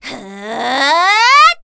assassin_w_voc_attack04_a.ogg